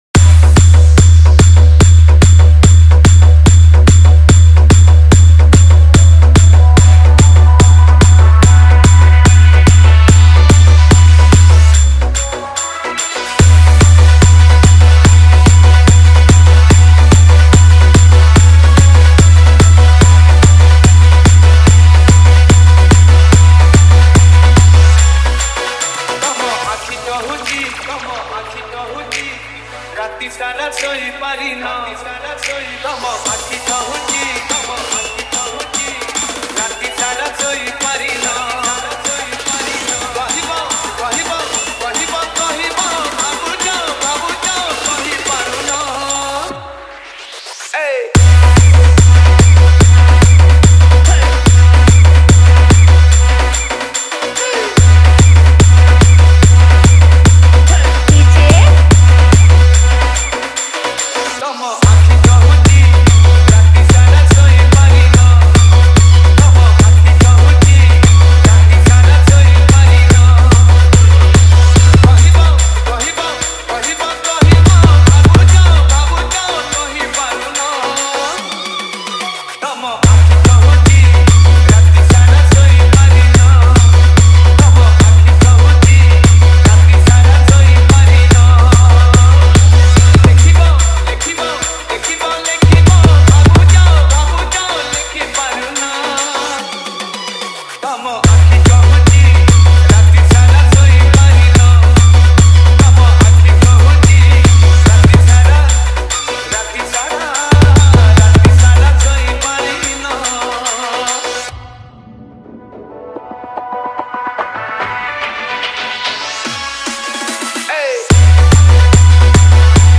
Humming Dance Remix